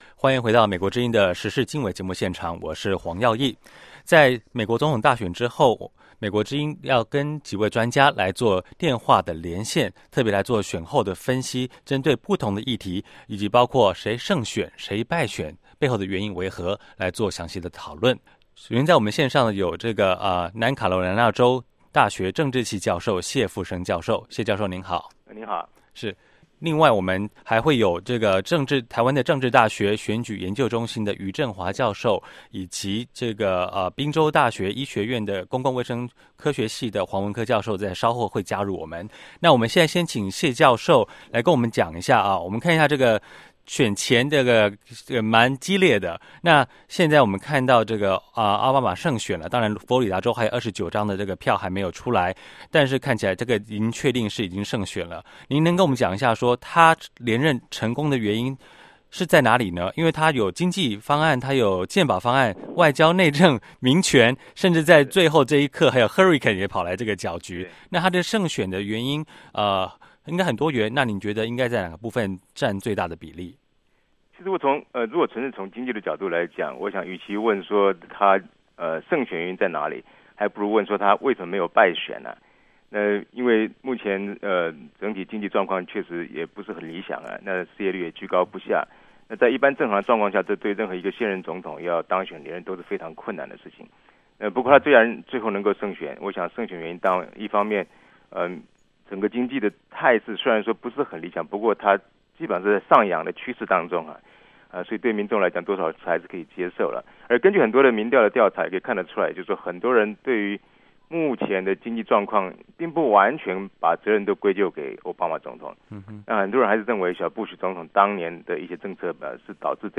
美国总统大选之后，美国之音的《时事经纬》节目特别现场连线，请专家分析选举结果以及美国政策走向。